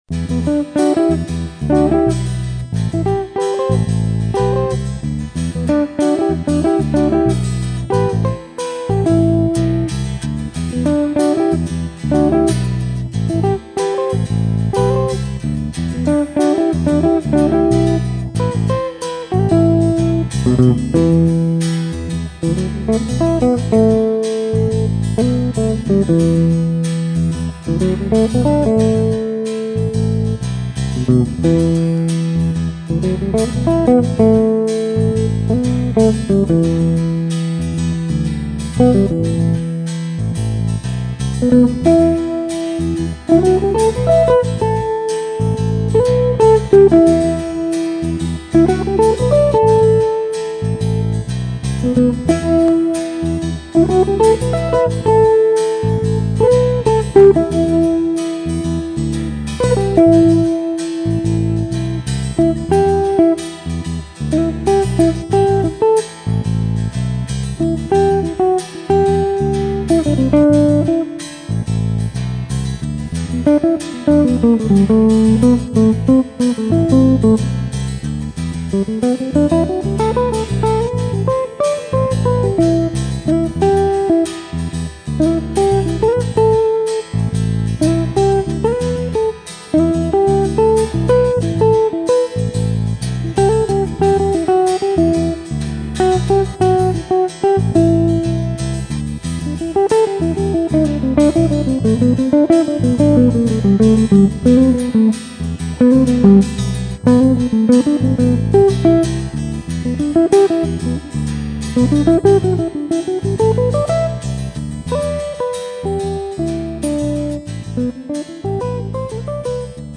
Moderato pop.
Brano semplice con apertura e sviluppo modale.